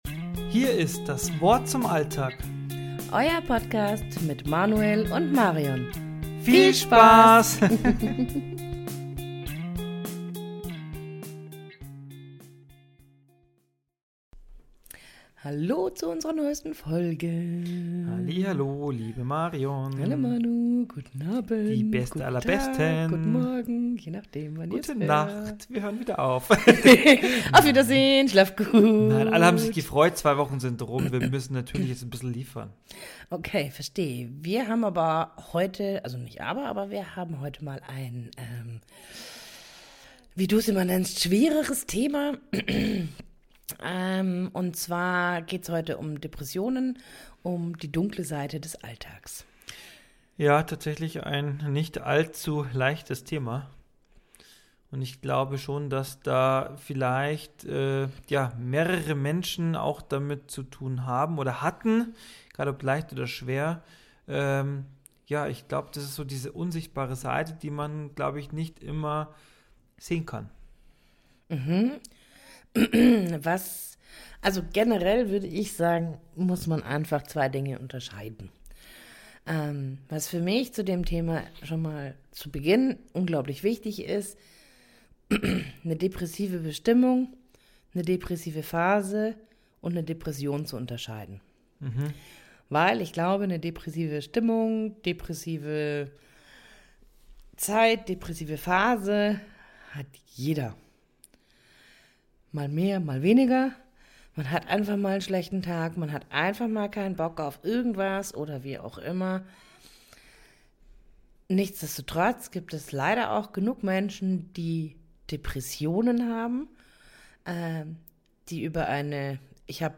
Beschreibung vor 1 Monat In dieser Podcast-Folge sprechen wir offen über Depressionen und Burnout. Wir beleuchten mögliche Ursachen, therapeutische Wege und Behandlungsmöglichkeiten und gehen darauf ein, wie wichtig Unterstützung durch Familie und Freunde ist. Ein ehrliches Gespräch über ein ernstes Thema, das viele Menschen betriff - und über das es mehr Offenheit braucht.